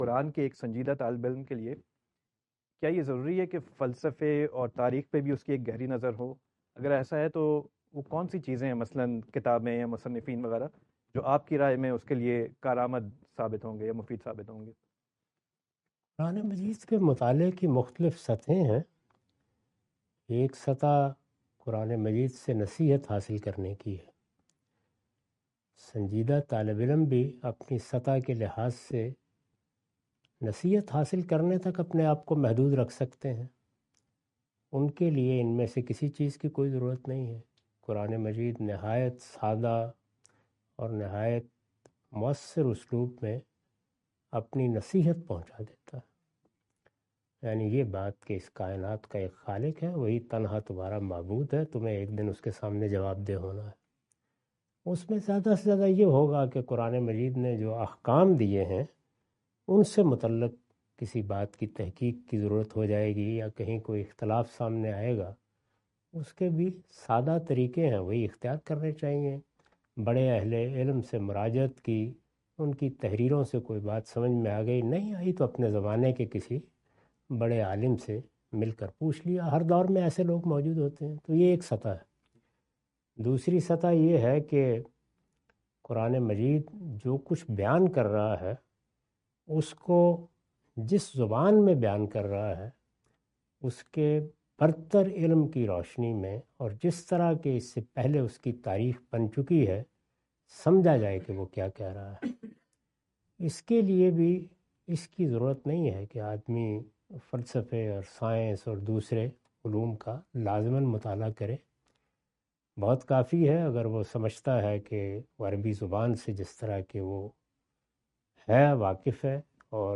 Category: Reflections / Questions_Answers /
In this video, Mr Ghamidi answer the question about "Is it necessary for a student of the Quran to acquire the knowledge of philosophy and history?".